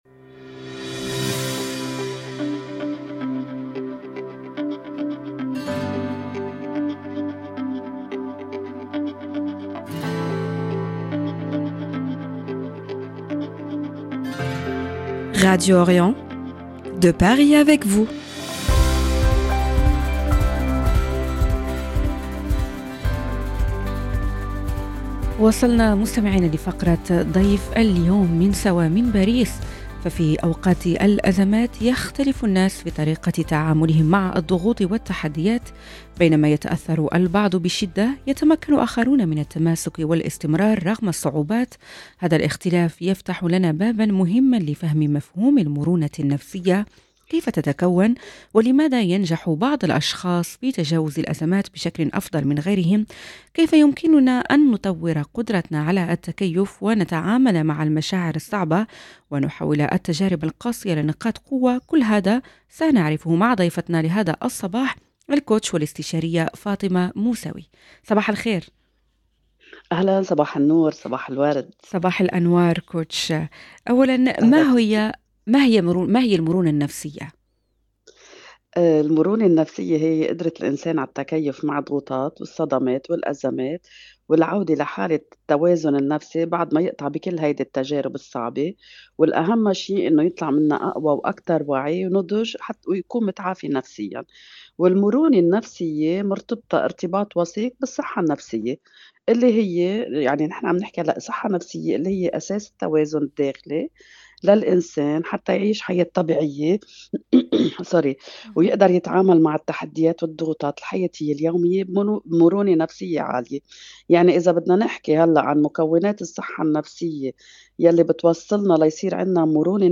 حوارنا